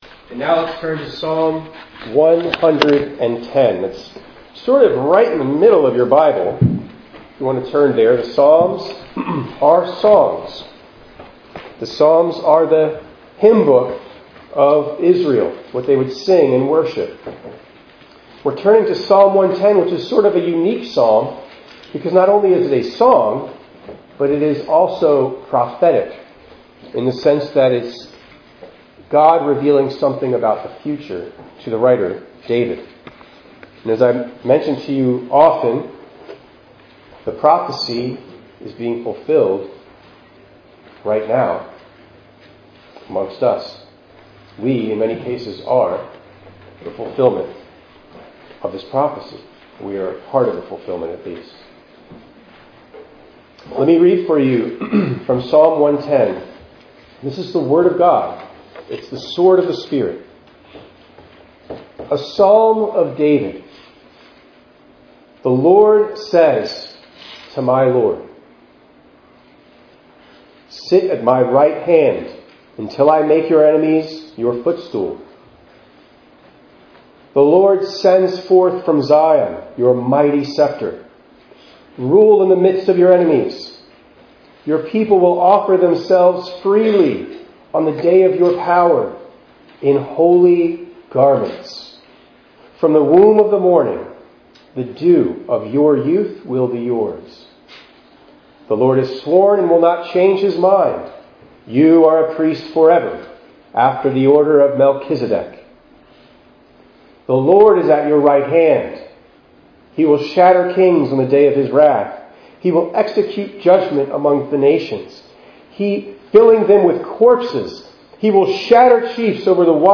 1_29_23_ENG_Sermon.mp3